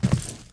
FootstepHeavy_Concrete 04.wav